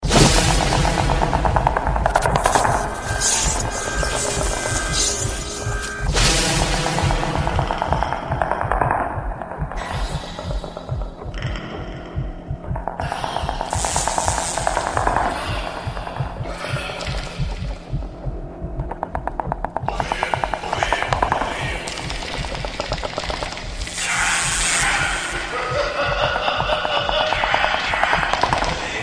Главная » Рингтоны » SMS рингтоны